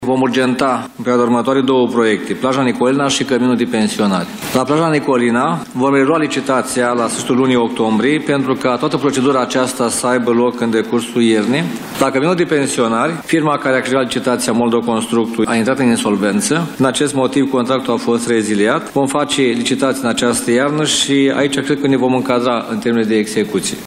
Primarul Iașului, Gheorghe Nichita, a prezentat, astăzi, în cadrul unei conferințe de presă, stadiul proiectelor realizate, în municipiu, cu fonduri europene.